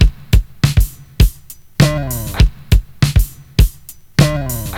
• 101 Bpm Drum Loop G Key.wav
Free drum beat - kick tuned to the G note. Loudest frequency: 1247Hz
101-bpm-drum-loop-g-key-2Tj.wav